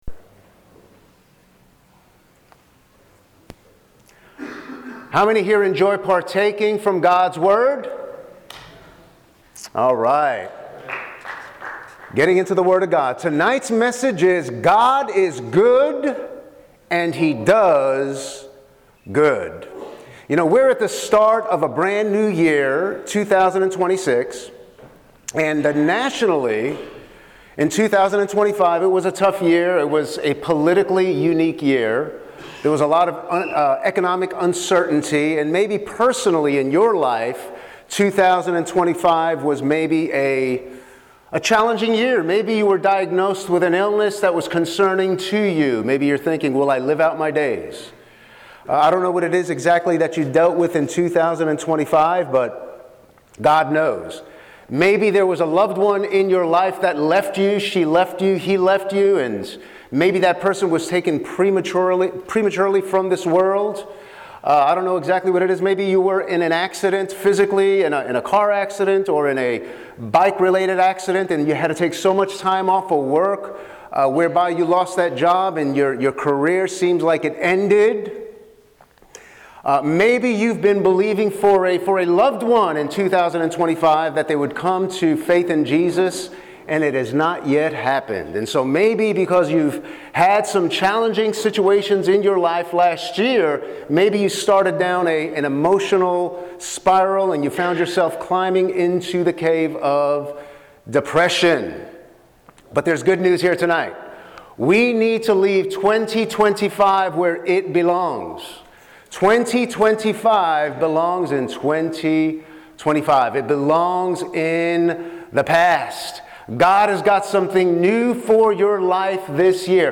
Precious People International -- Sermon Audio from 2016